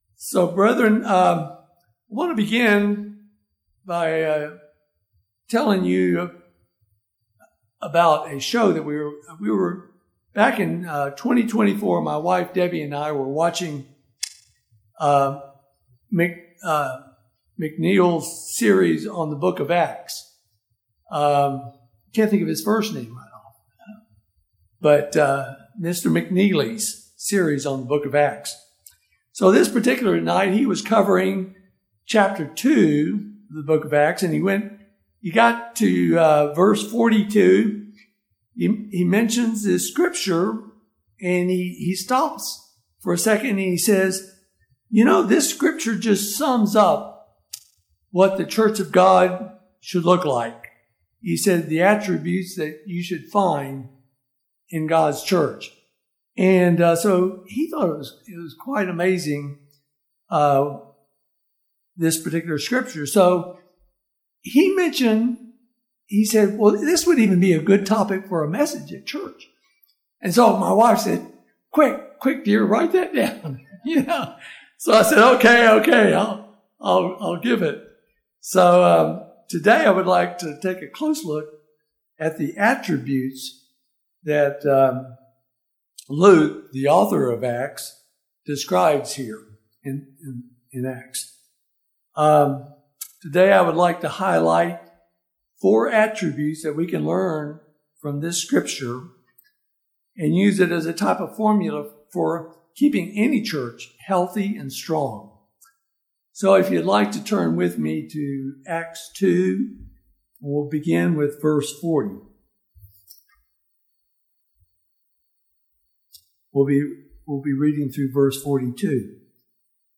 Sermons
Given in Lawton, OK